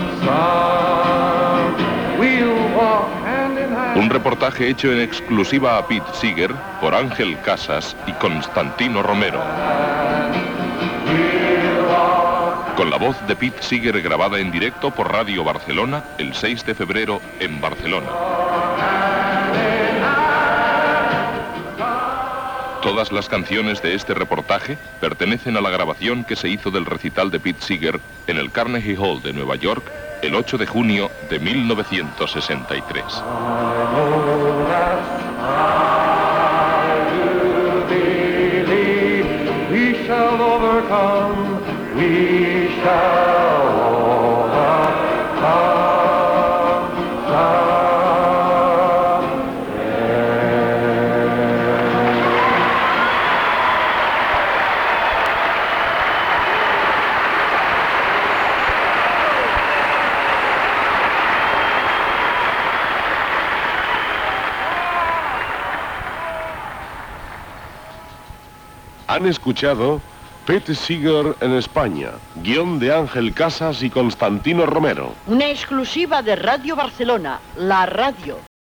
Temes musicals i entrevista al cantant Pete Seeger que havia actuat a Espanya.
Comiat del programa, amb els crèdits.
Musical